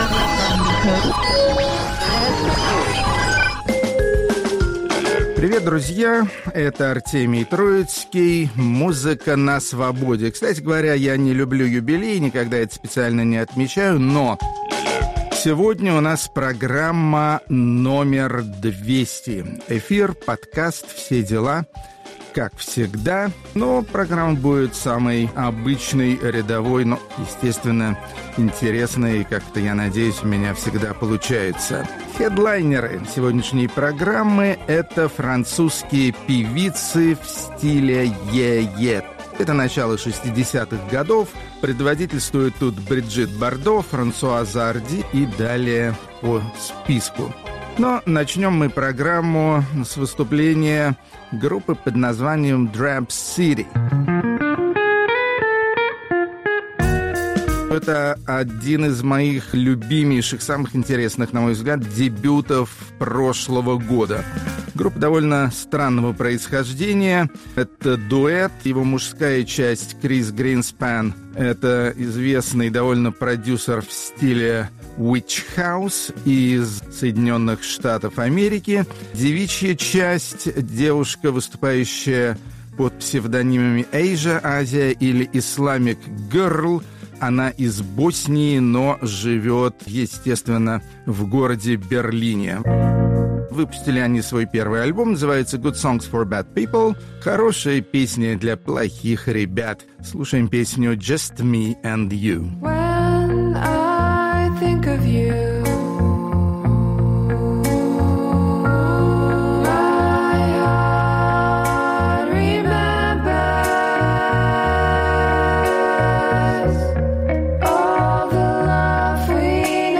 Les etoiles французской эстрады, красавицы той благословенной поры, когда рок-н-ролл был не только жив, но вполне молод и чертовски привлекателен. Рок-критик Артемий Троицкий не признаёт юбилейных передач: чем попусту праздновать, лучше поговорить о хорошей музыке и звонких девичьих голосах.